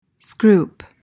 PRONUNCIATION: (skroop) MEANING: verb intr.: To make a scraping or grating sound. noun: A scraping sound, especially the rustle of a silk fabric.